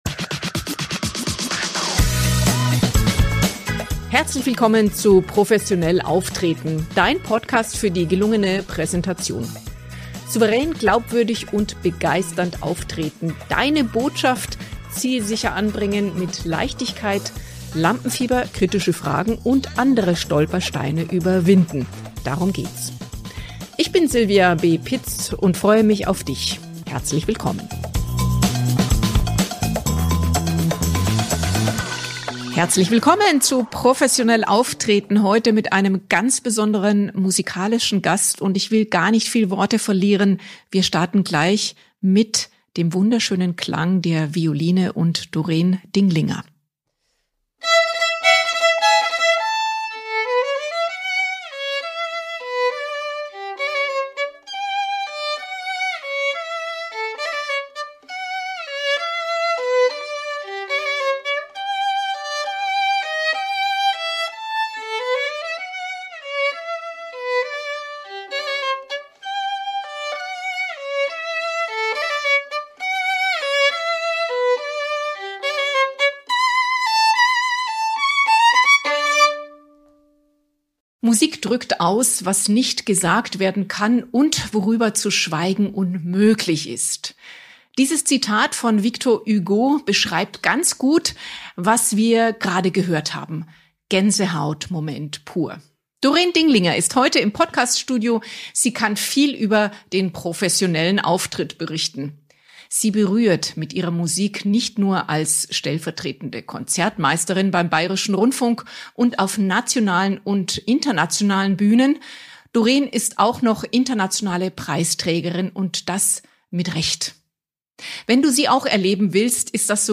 In dieser inspirierenden Folge habe ich die außergewöhnliche Musikerin zu Gast.